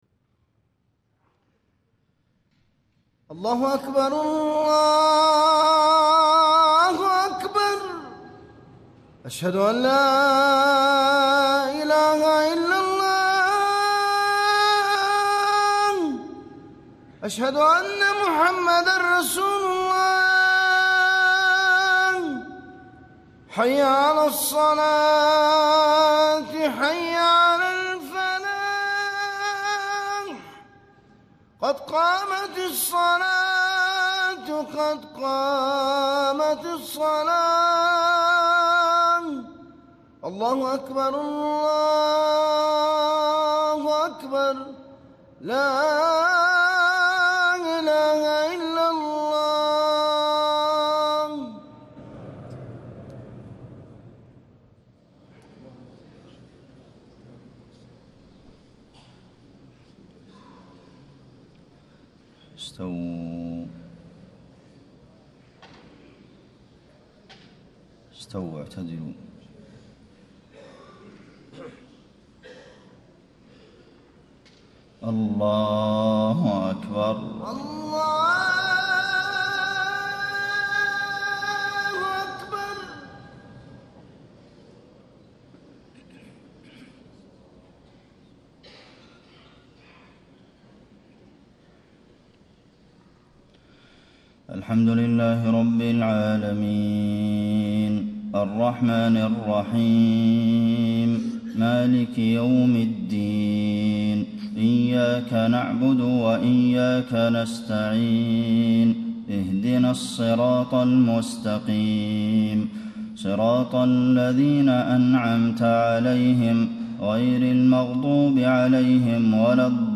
صلاة الفجر 1 - 2 - 1436 تلاوة من سورة المجادلة > 1436 🕌 > الفروض - تلاوات الحرمين